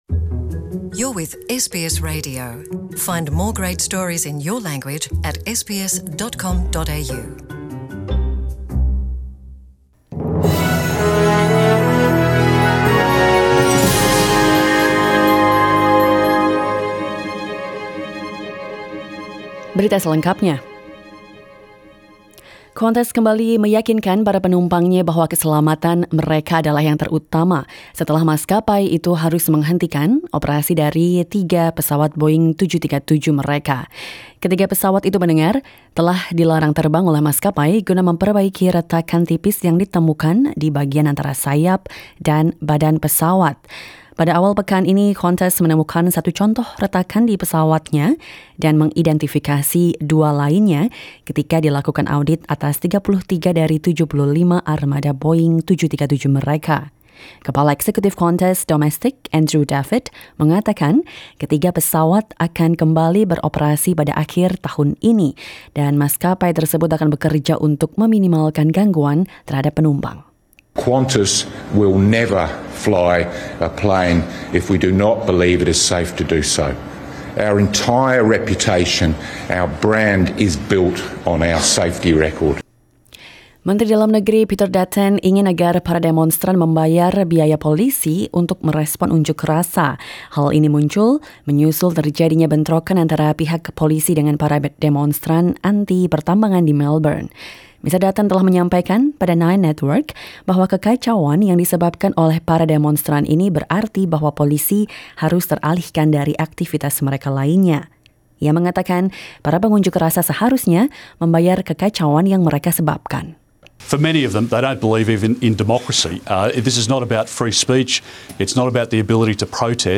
SBS Radio News in Indonesian - 1 November 2019